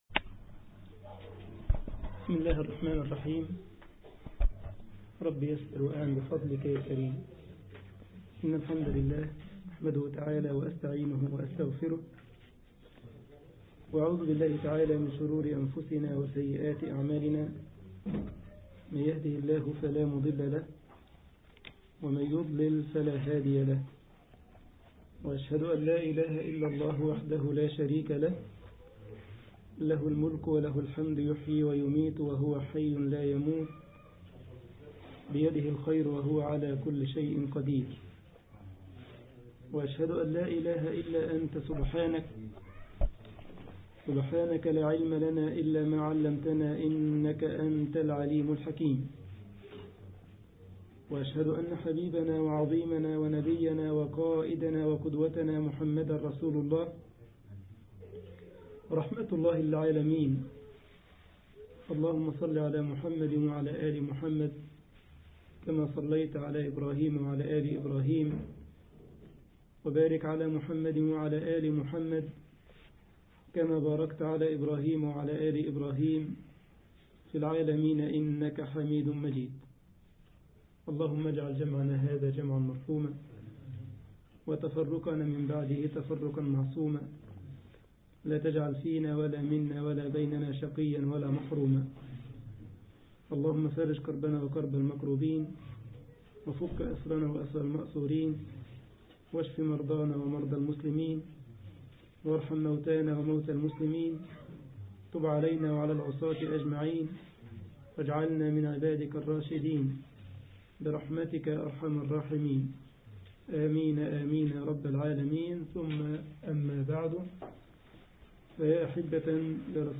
مصلى جامعة السارلند ـ ألمانيا